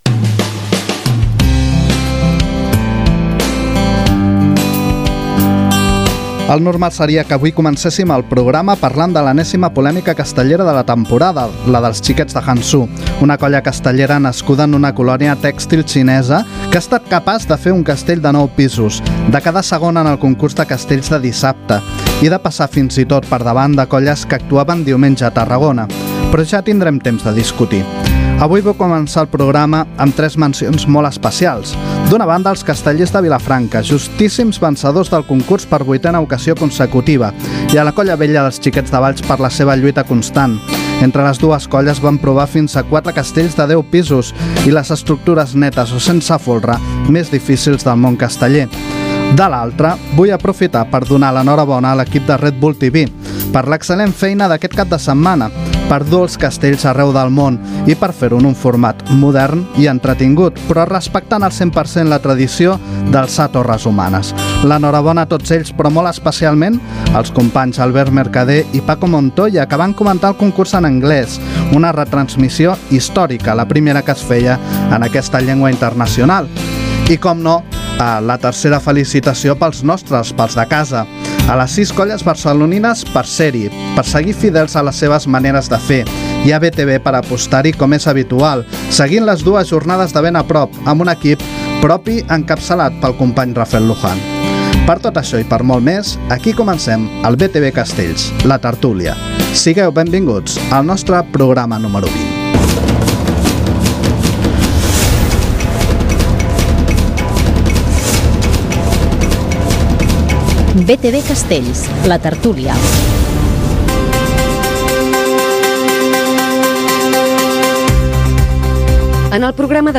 Betevé castells, la tertúlia